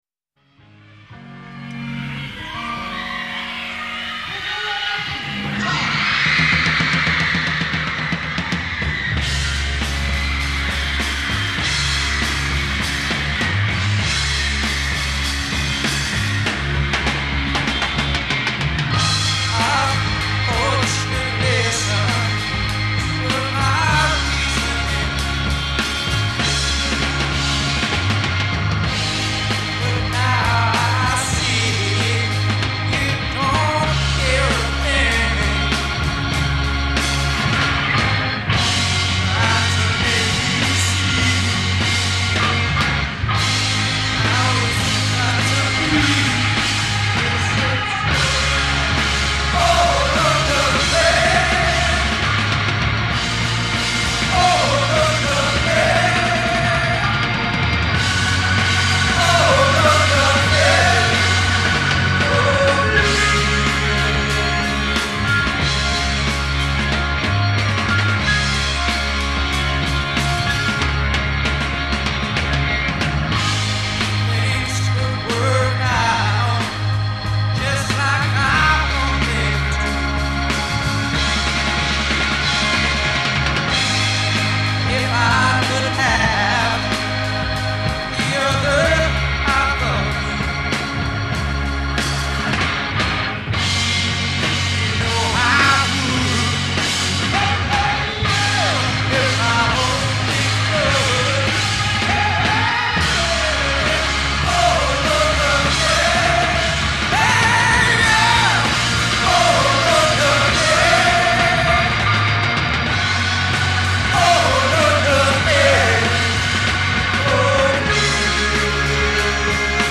Compare with the live version of "